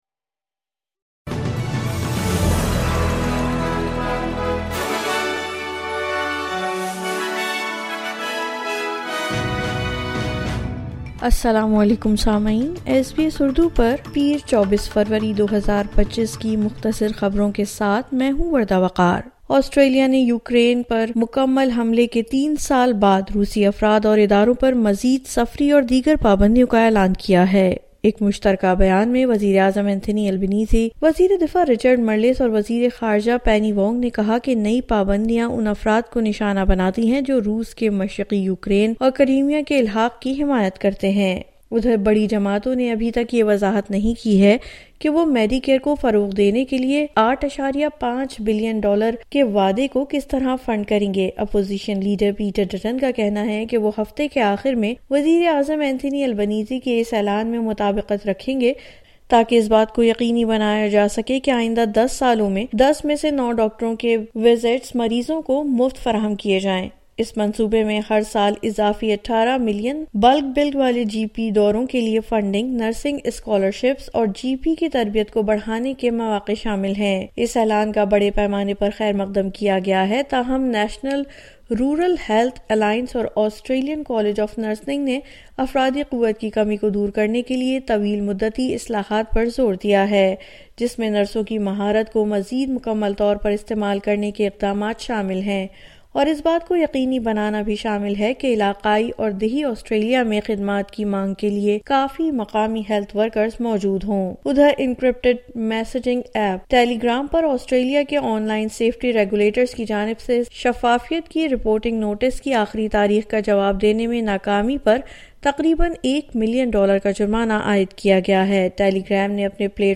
مختصر خبریں: پیر 24 فروری 2025